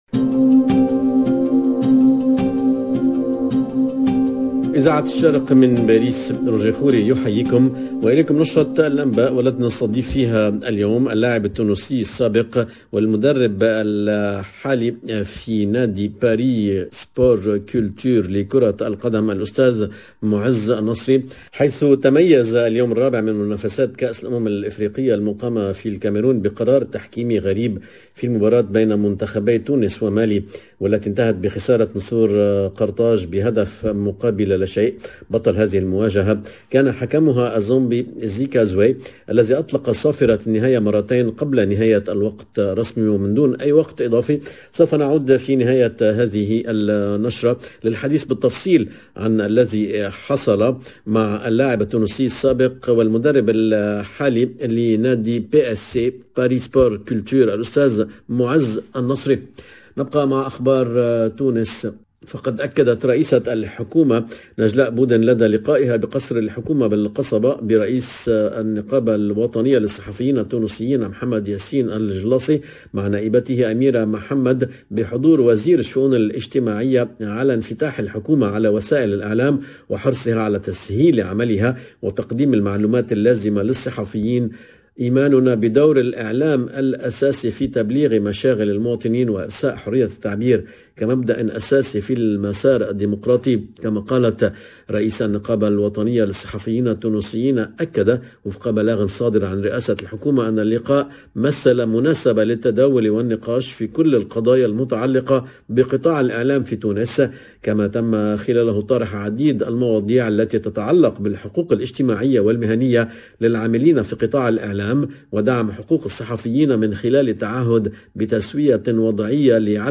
LE JOURNAL DE MIDI 30 EN LANGUE ARABE DU 13/01/22